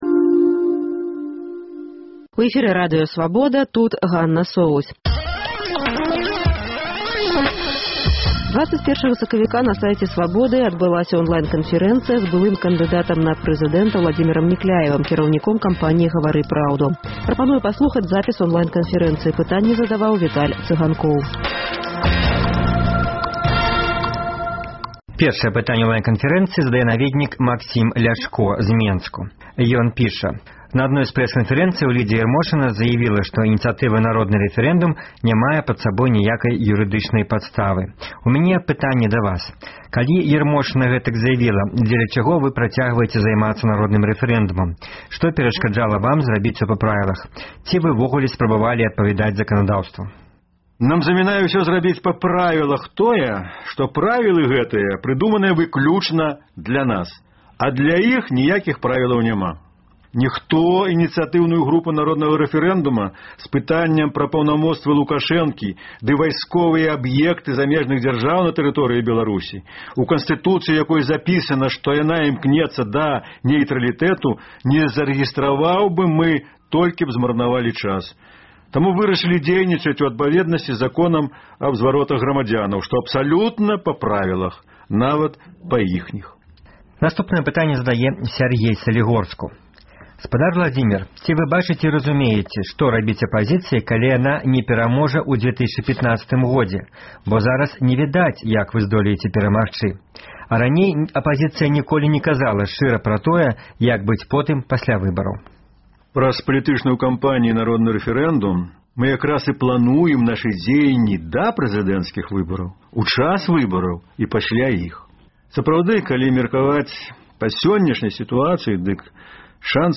На вашыя пытаньні падчас онлайн-канфэрэнцыі адказаў лідэр кампаніі «Гавары праўду», паэт і палітык, былы кандыдат у прэзыдэнты Ўладзімер Някляеў.